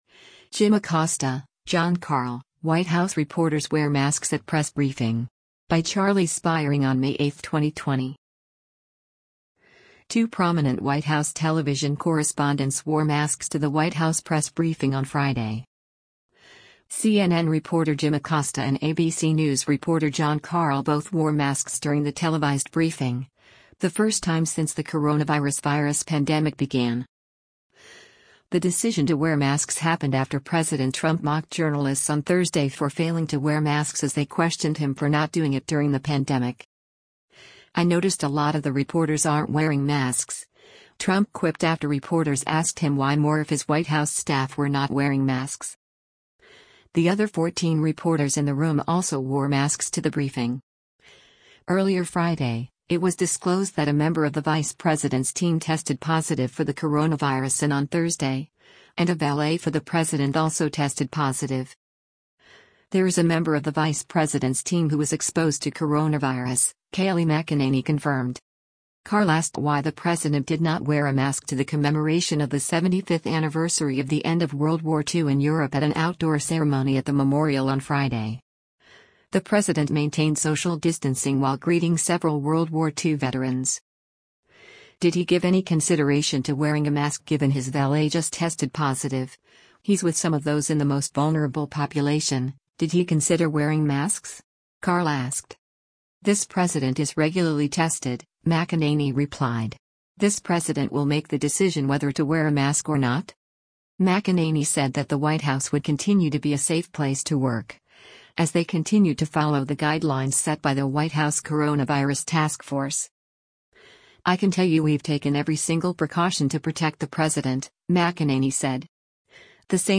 CNN reporter Jim Acosta and ABC News reporter Jon Karl both wore masks during the televised briefing, the first time since the coronavirus virus pandemic began.
White House reporters wear masks at White House press briefing